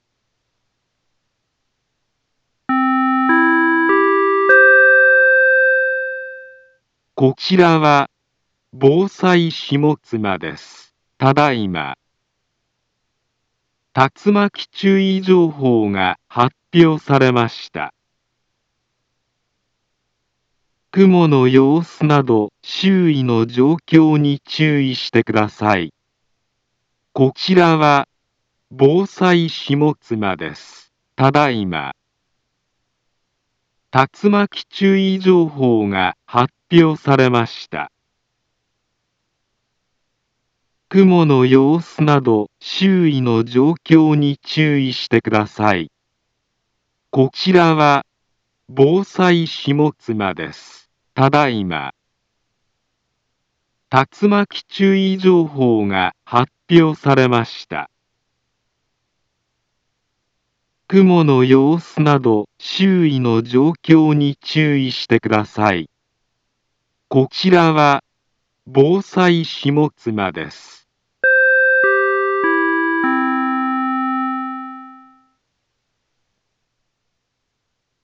Back Home Ｊアラート情報 音声放送 再生 災害情報 カテゴリ：J-ALERT 登録日時：2025-09-18 14:58:33 インフォメーション：茨城県北部、南部は、竜巻などの激しい突風が発生しやすい気象状況になっています。